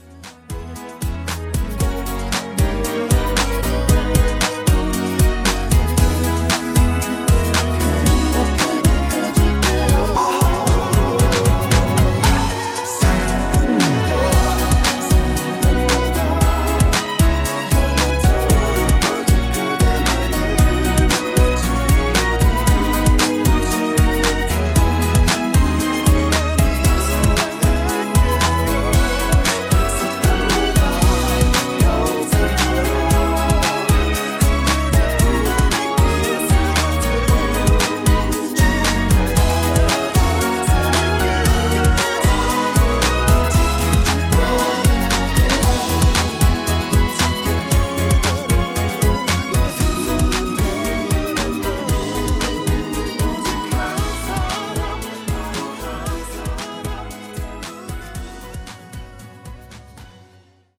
음정 -1키 3:45
장르 가요 구분 Voice MR